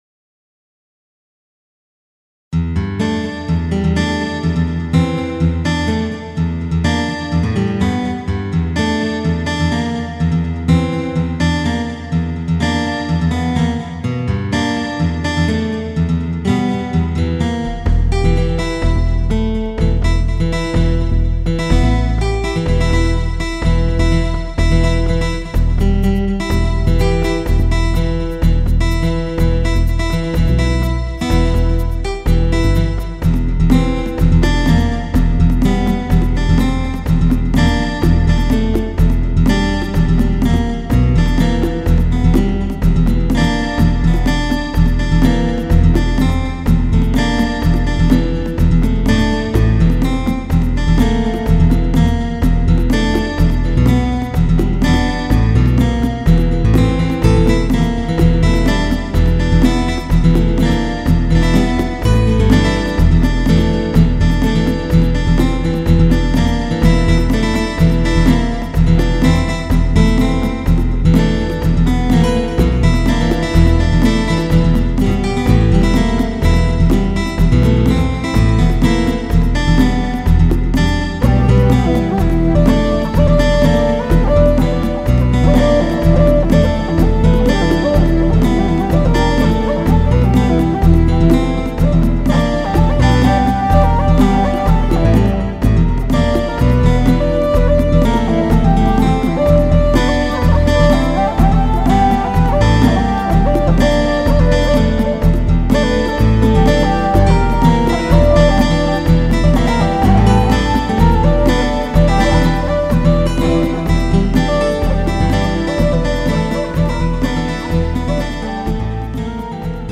2 morceaux de guitare en 2 versions à partir du même algorithme - 1/f Noise : Ur pennad (Un moment) et Un tennad (Un coup).
1/f Noise (--/--) Mi (E) - Penta1 125
Drum loop : Arythm